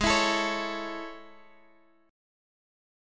Listen to A/Ab strummed